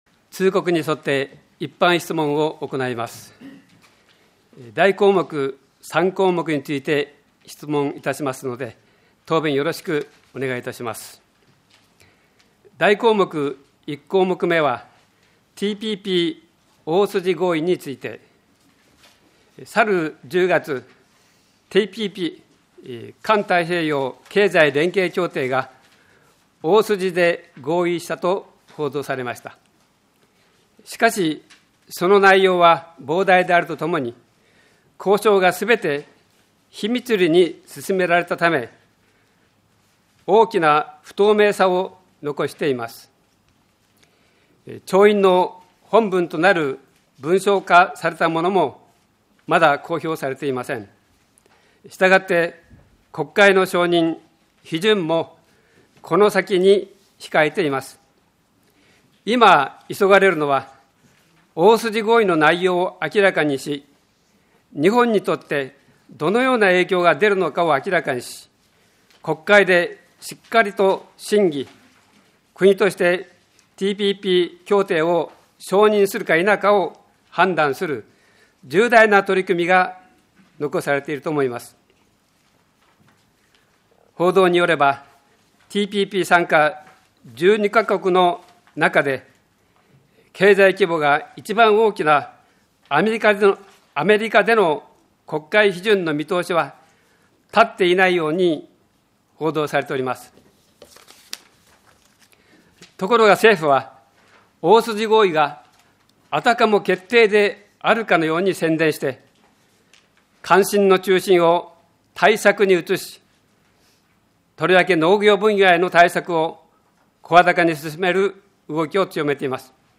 平成27年第4回定例会 一般質問録音音声 - 留萌市ホームページ
議会録音音声